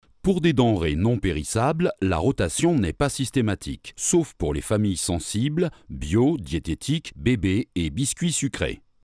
Voix homme grave 30-50 ans.
Sprechprobe: eLearning (Muttersprache):
Deep warm man voice for e-learning hold on messages audioguides commercials ..